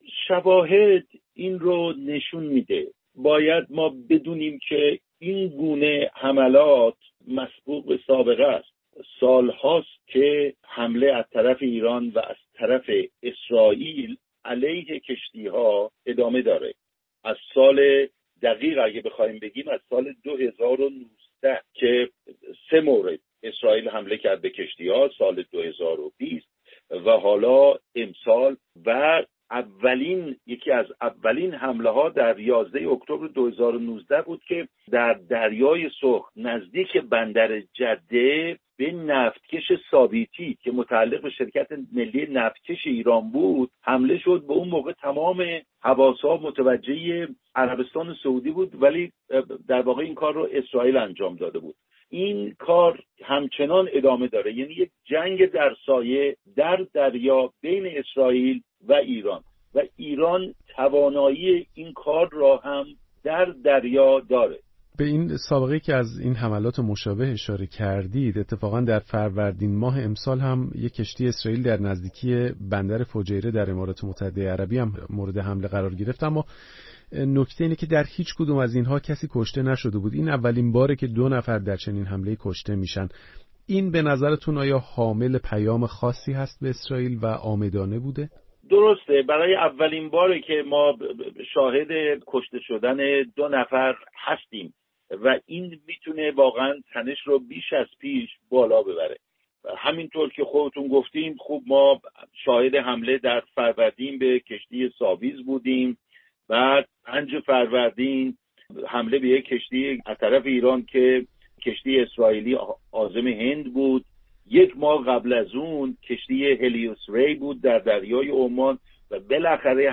گفت‌وگویی